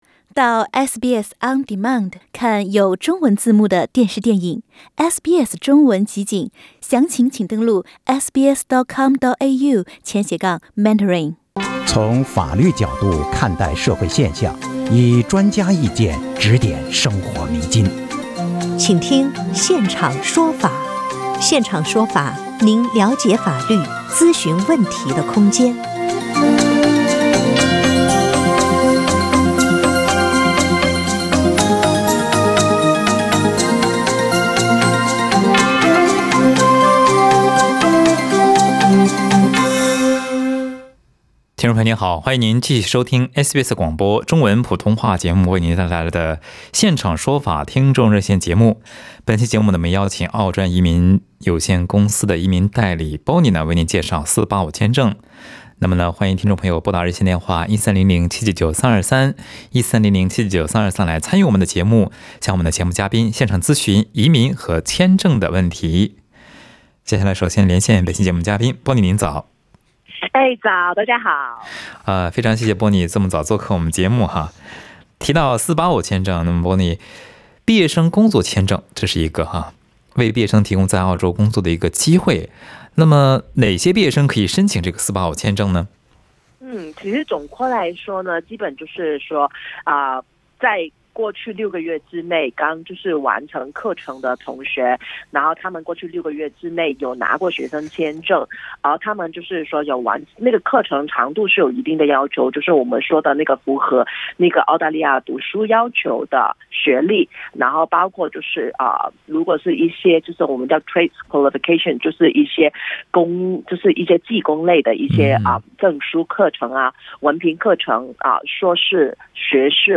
有听众朋友在节目中向嘉宾咨询了陪读签证持有者能否打工，能否做来澳求学的亲戚家未成年孩子的监护人，829签证的居住条件测试等移民签证问题。